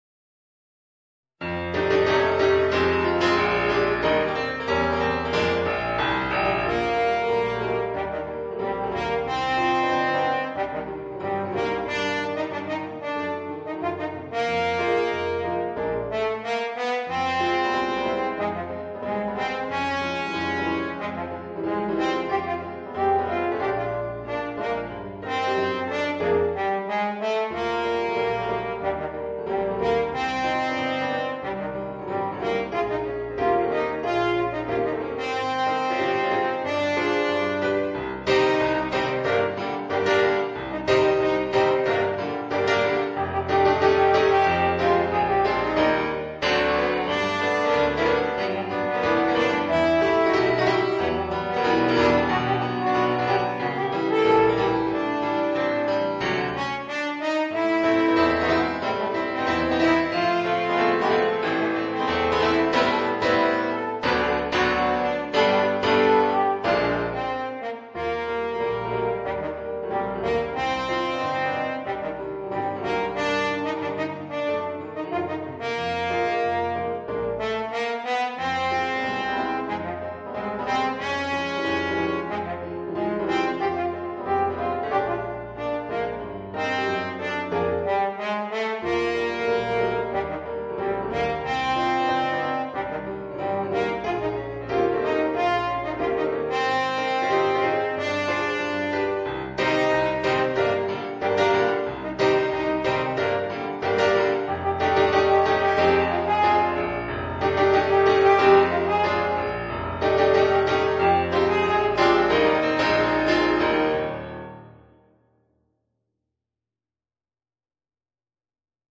Es-Horn & Klavier